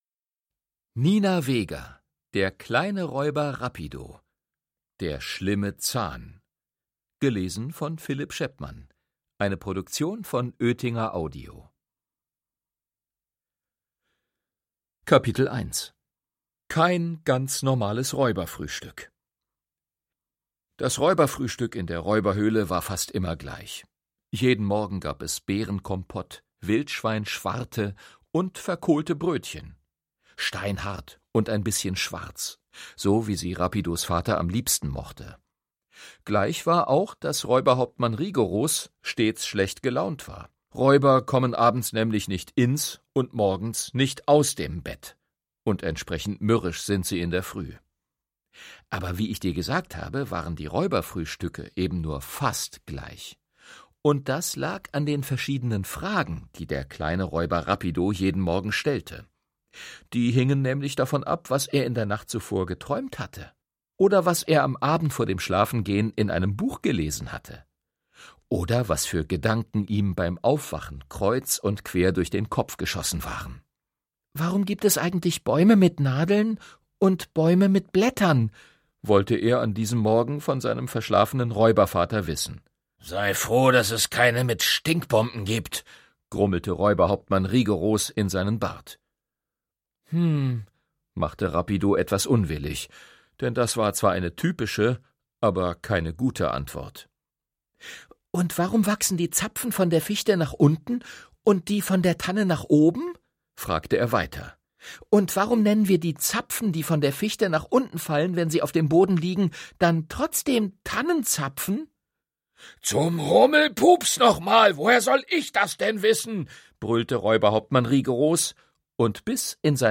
Hörbuch: Der kleine Räuber Rapido 3.